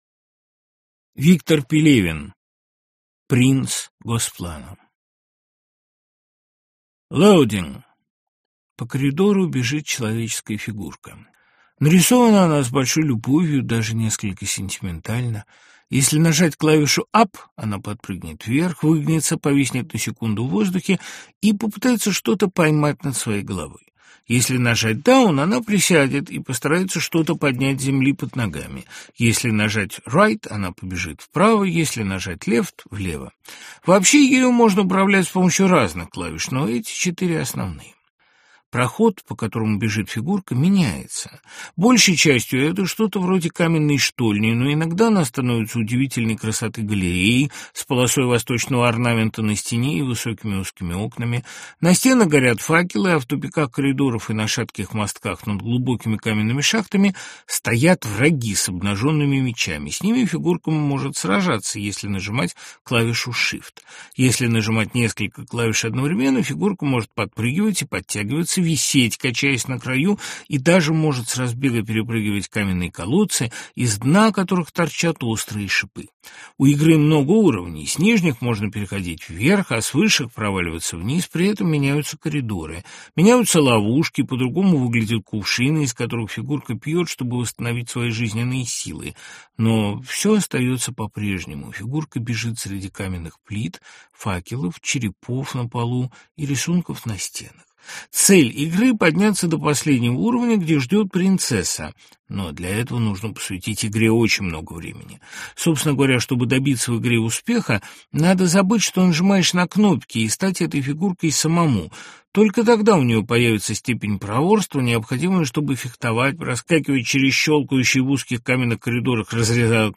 Аудиокнига Пелевин В. Принц Госплана в исполнении Дмитрия Быкова + Лекция Быкова Дмитрия | Библиотека аудиокниг
Aудиокнига Пелевин В. Принц Госплана в исполнении Дмитрия Быкова + Лекция Быкова Дмитрия Автор Виктор Пелевин Читает аудиокнигу Дмитрий Быков.